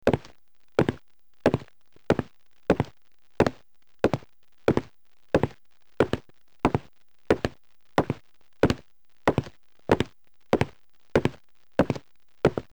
Escolteu-lo: és un so d'uns passos.